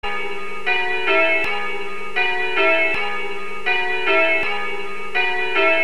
...sona la "p�ria" l'nostre ciampane... ...suonano in concerto le nostre campane cliccare x attivare suono >>>>